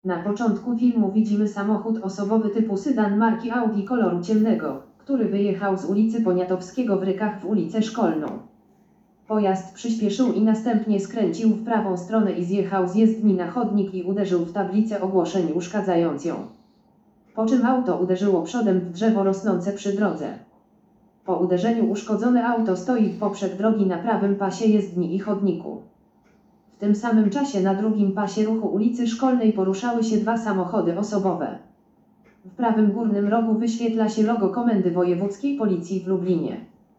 Nagranie audio Audiodeskrypcja_filmu_brawurowa_jazda_zakonczyla_sie_na_drzewie.m4a